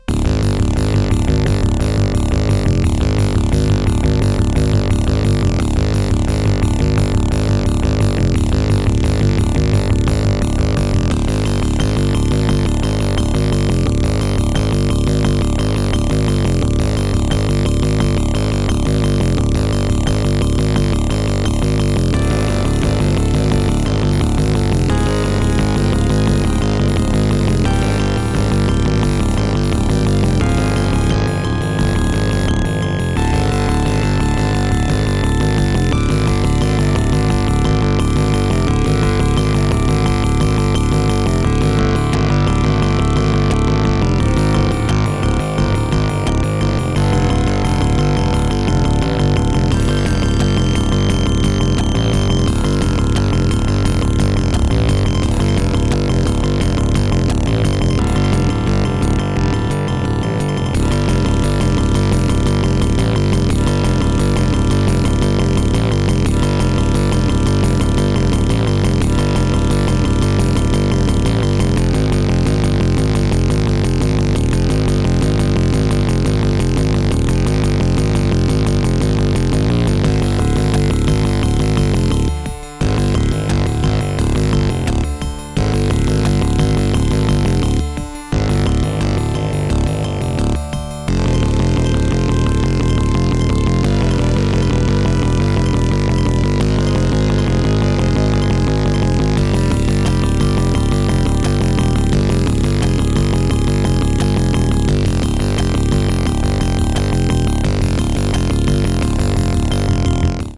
Music: GM midi
Creative SoundBlaster 2.0 ct1350